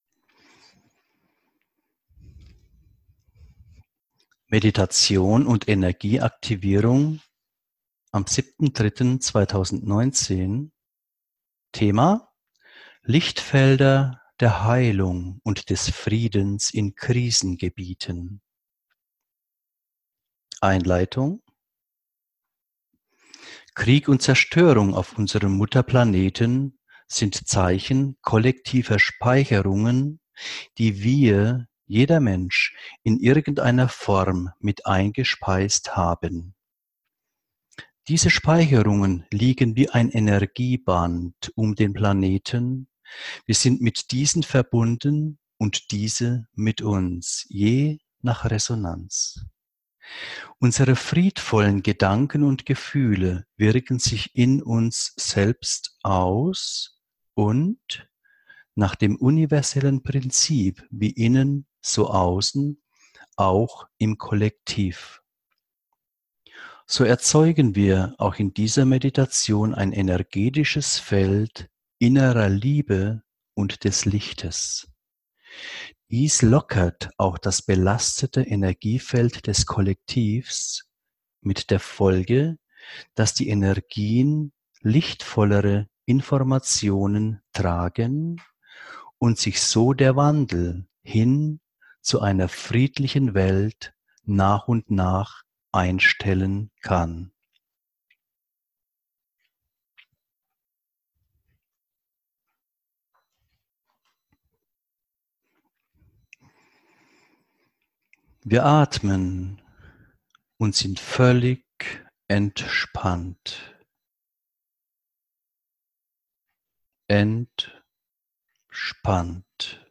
Von den Mitgliedern unserer kleinen Meditationsgruppe stammt diese geführte Meditation zum Thema Lichtfelder der Heilung und des Friedens in Krisengebieten.